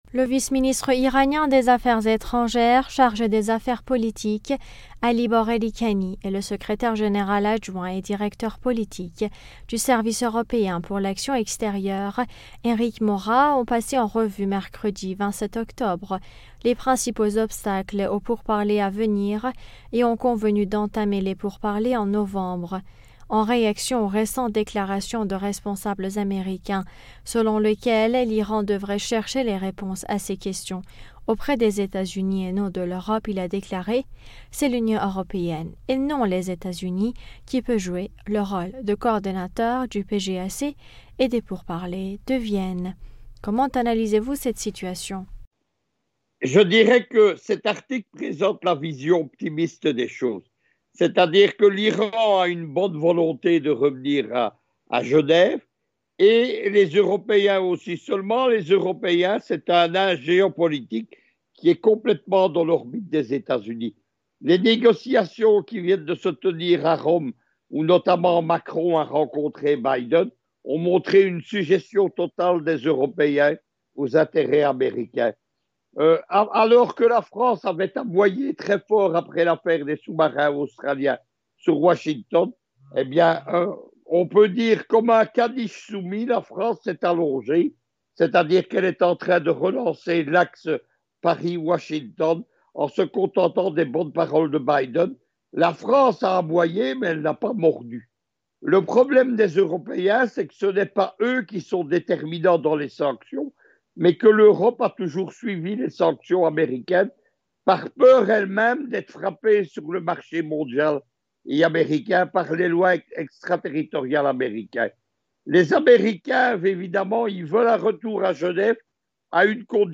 géopoliticien s'exprime sur le sujet.